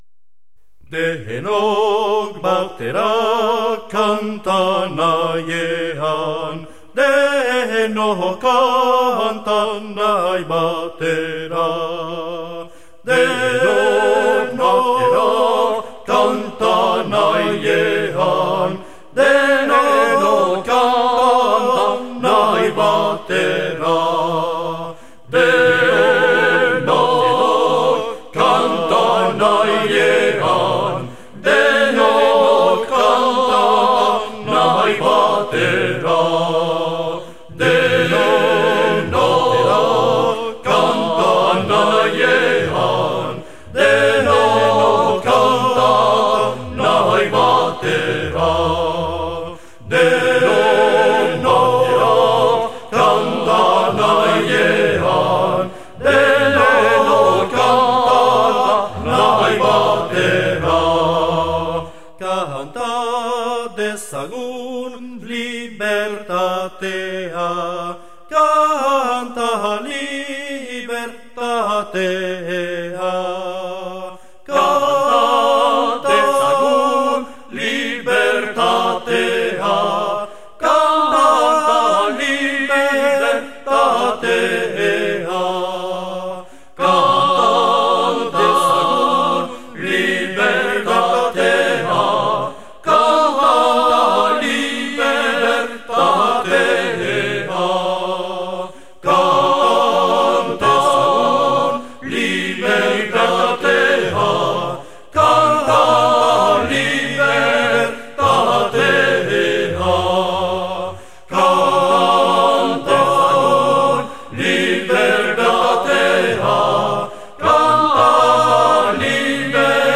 Dultziñak, txistuak eta klarinetea
Baxua
Akordeoia
Biolontxeloa
Klarinetea